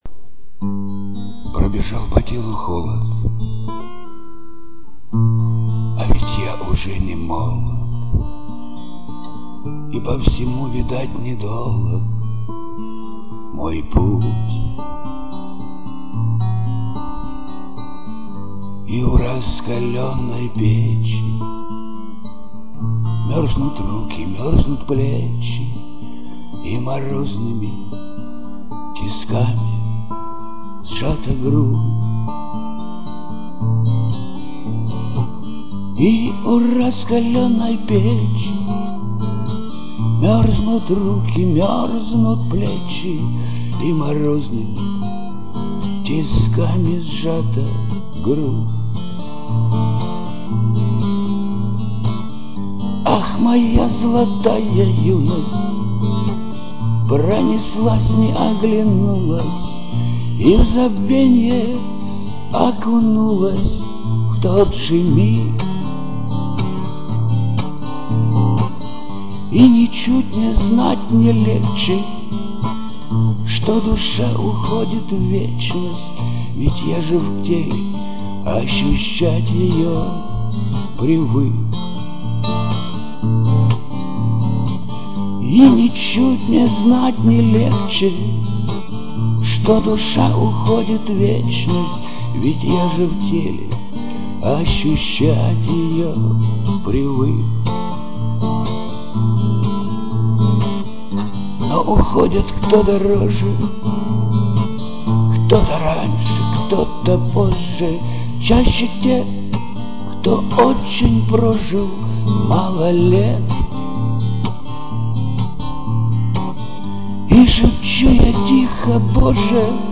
Русский шансон Авторская песня Шансон Барды Авторские песни